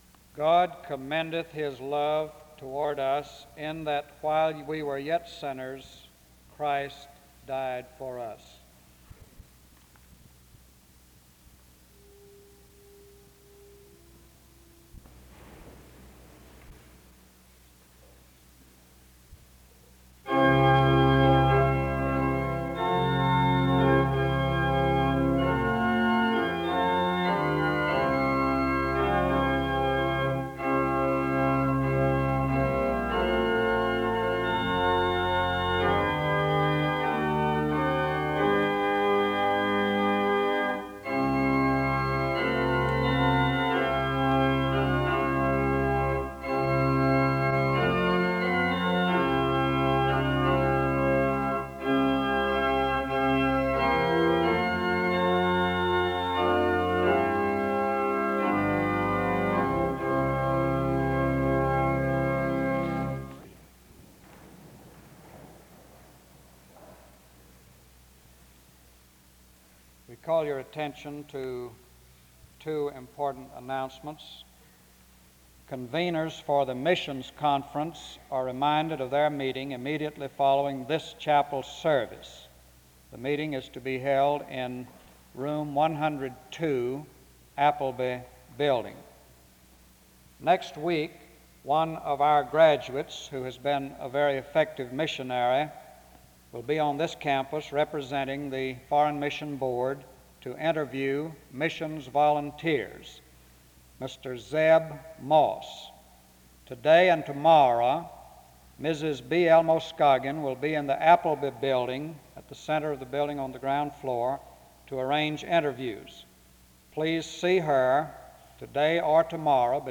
The service begins with reading Romans 5:8 and music from 0:00-1:04. There are opening announcements and prayer from 1:10-5:56.